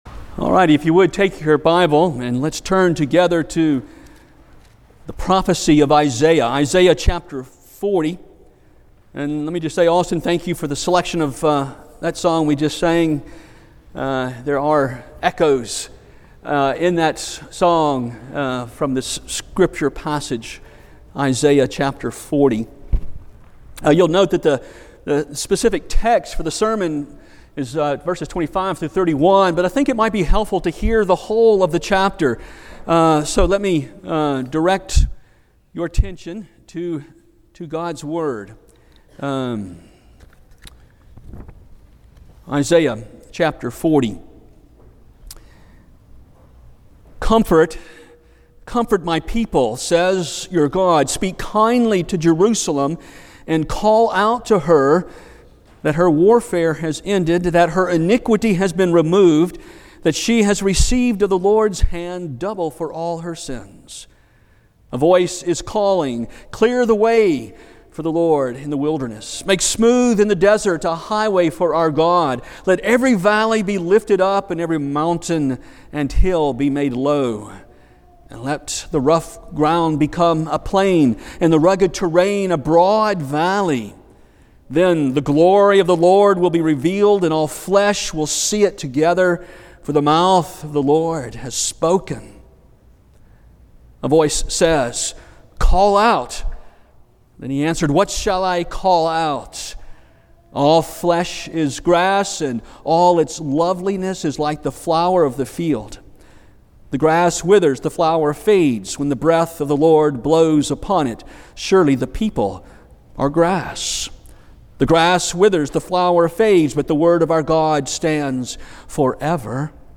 Passage: Isaiah 40:25-31 Service Type: Sunday Morning Isaiah 40:25-31 « The Business of the Father John The Baptist